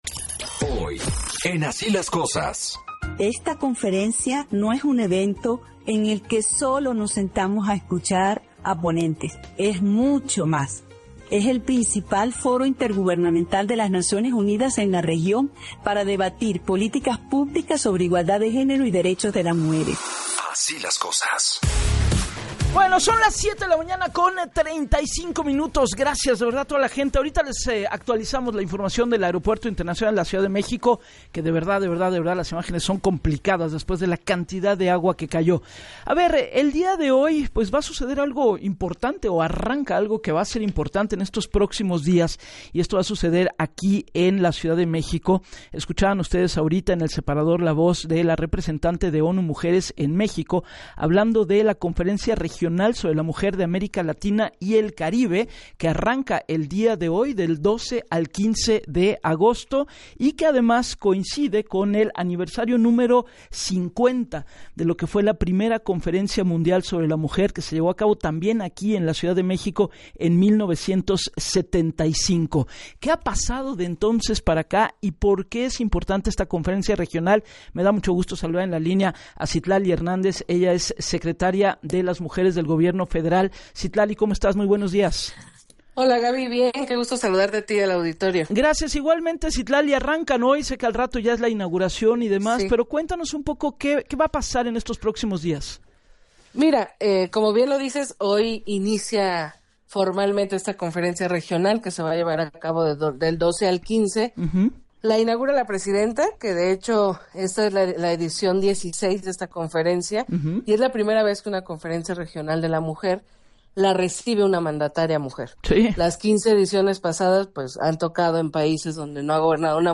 Este año, la Secretaría de las Mujeres fijó como eje temático el debate sobre los cuidados, detalló la secretaria en entrevista para “Así las Cosas”, con Gabriela Warkentin.